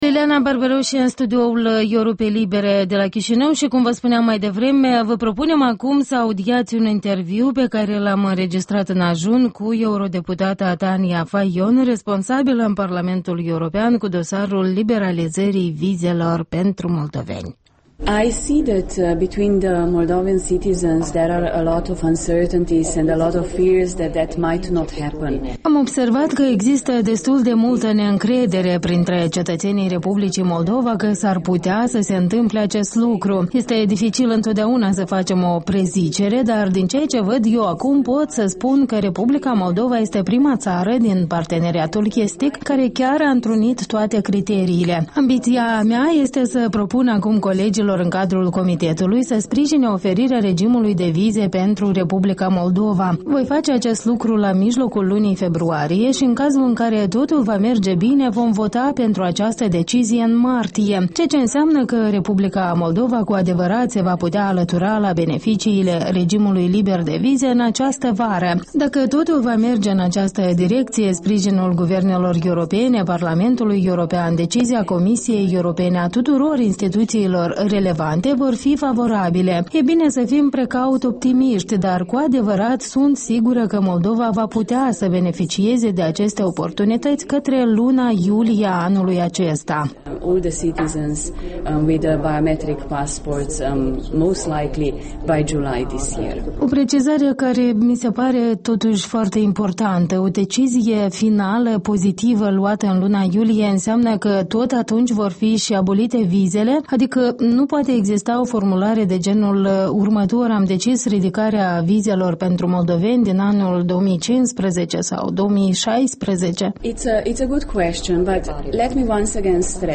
Interviul dimineții: cu europarlamentara Tania Fajon despre liberalizarea vizelor